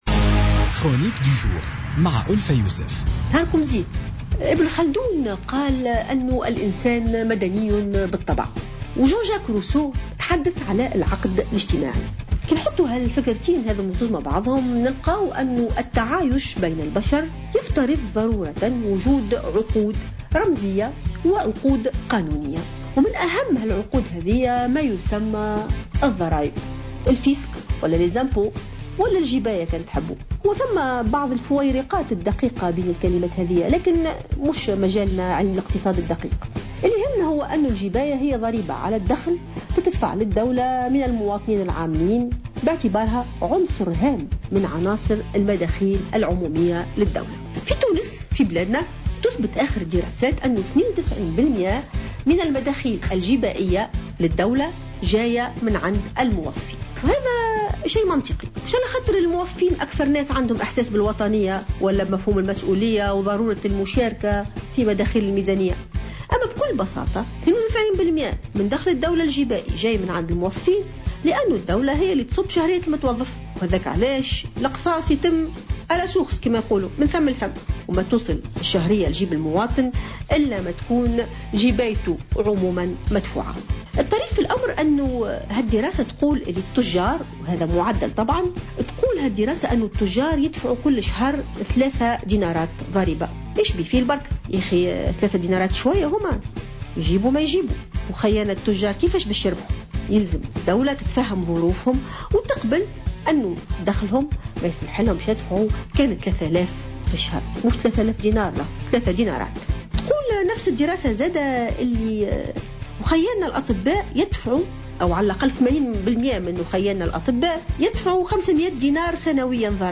علّقت الجامعية والمفكرة ألفة يوسف في افتتاحية اليوم الأربعاء على مسألة التهرب الضريبي.